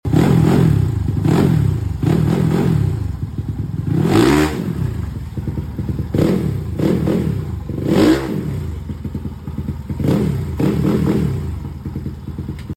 Cek sound knalpot kenochi sound effects free download